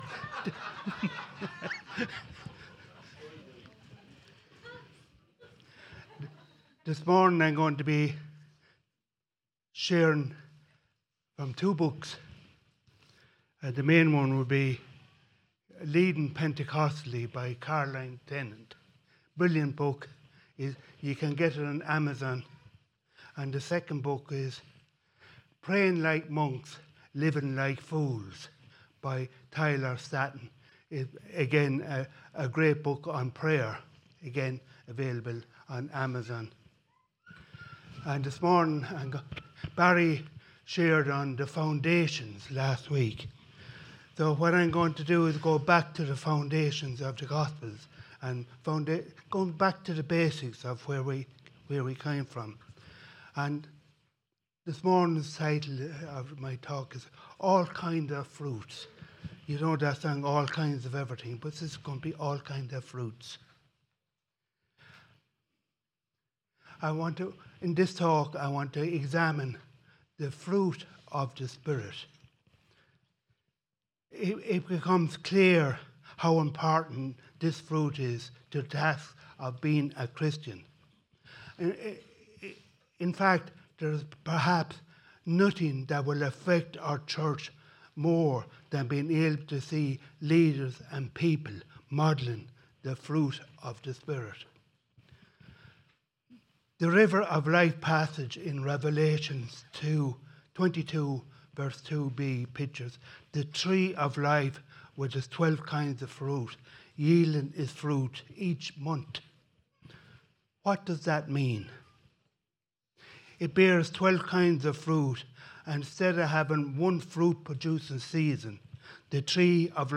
A message from the series "Messages 2026."